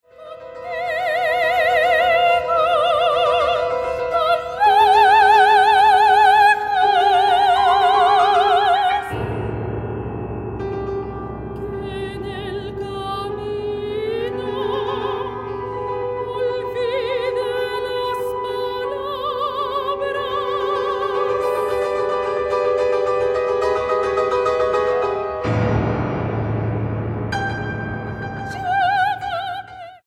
barítono
piano